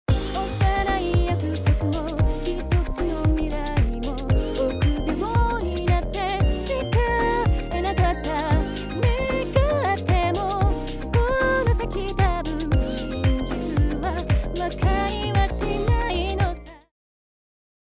使用前の音源では、音量のばらつきやブレス音が目立っていますが、DynAssistを適用すると音量が均一になりブレス音が自然に処理され、聴きやすいサウンドに仕上がりました。